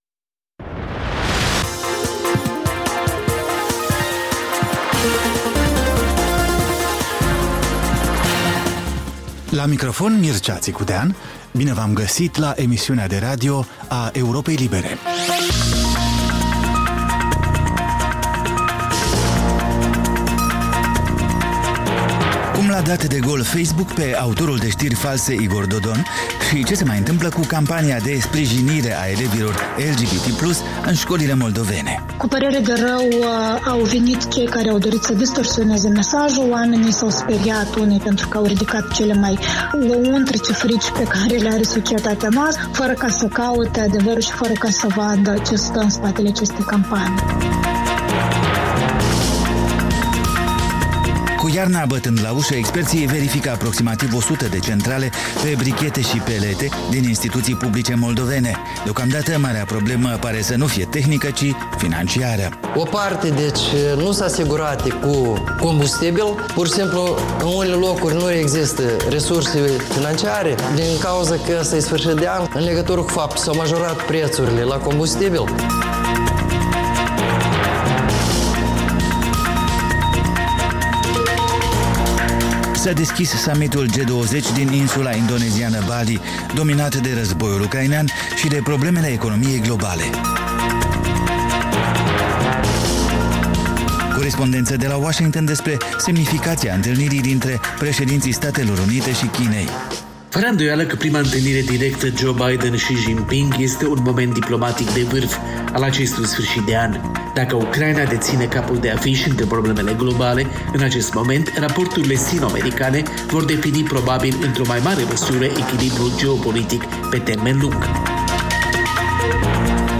Ştiri, interviuri, analize. Programul care stabileşte agenda zilei.